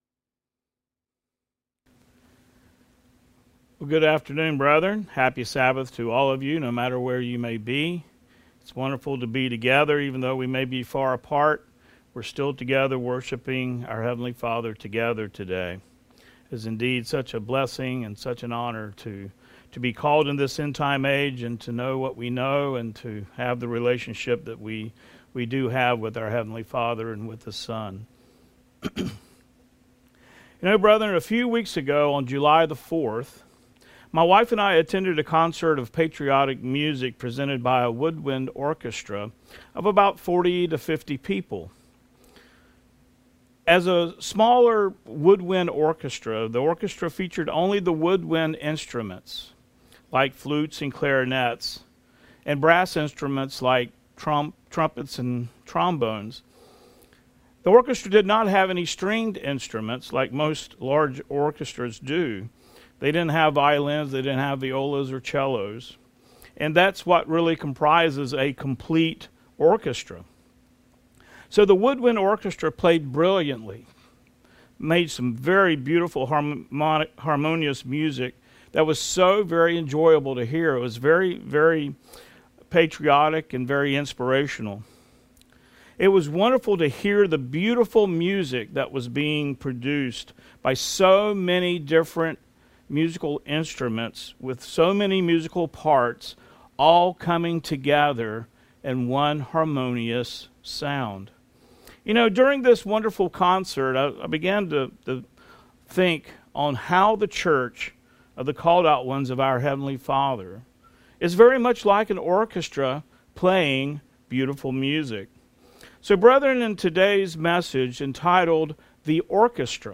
New Sermon | PacificCoG
From Location: "Houston, TX"